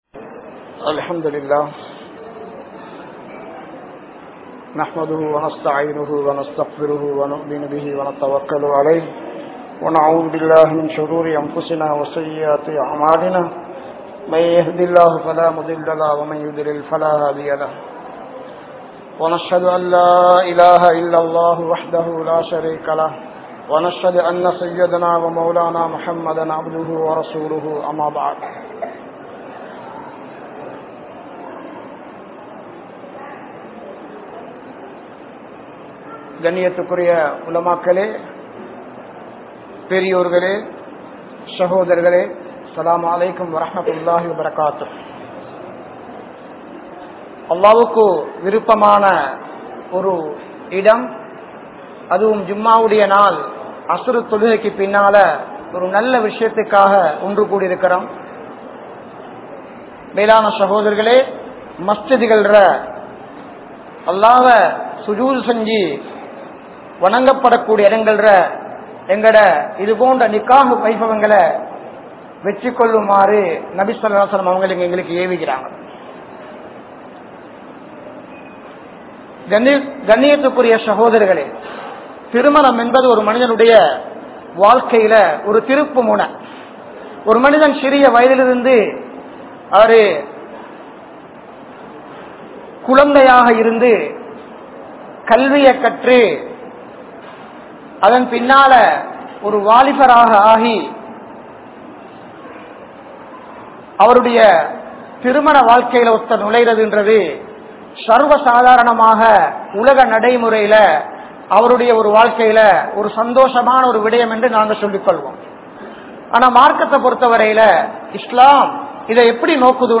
Nikkah Ean Avasiyam?(திருமணம் ஏன் அவசியம்?) | Audio Bayans | All Ceylon Muslim Youth Community | Addalaichenai